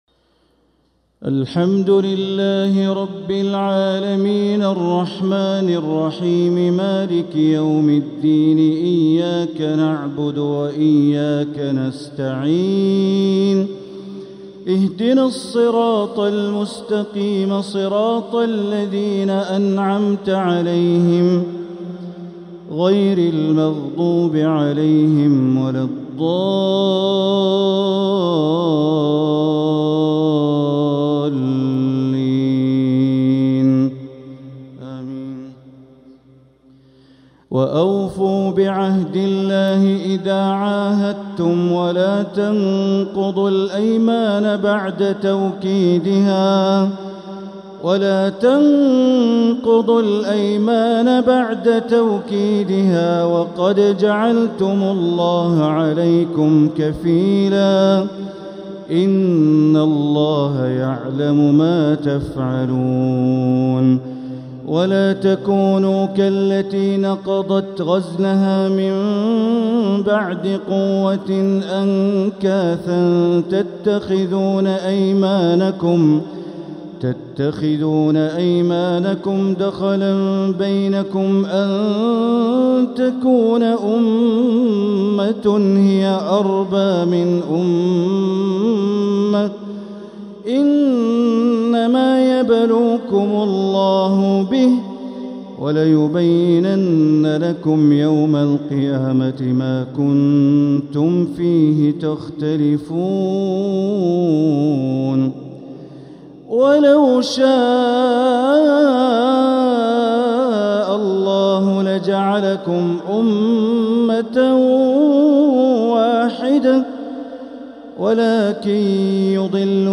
تلاوة من سورة النحل | صلاة الجمعة 6 شوال 1446هـ > 1446هـ > الفروض - تلاوات بندر بليلة